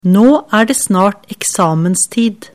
setningsdiktat_skolesystemet08.mp3